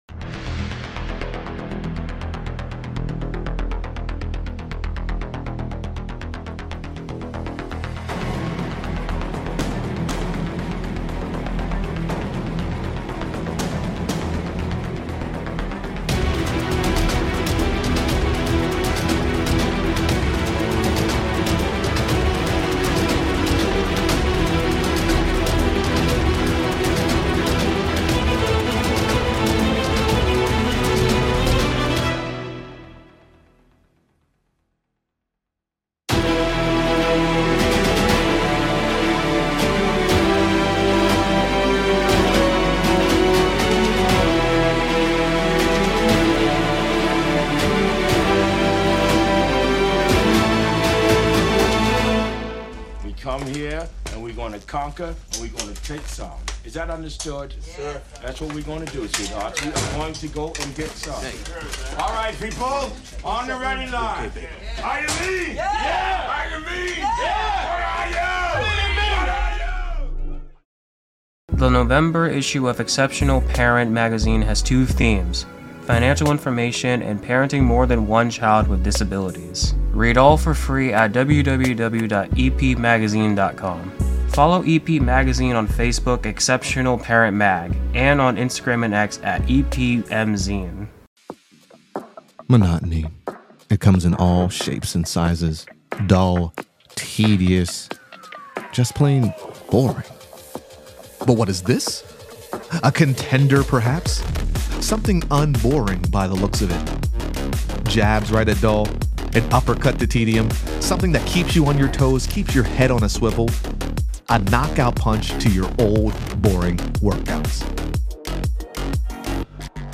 💬 A Conversation About Service and Legacy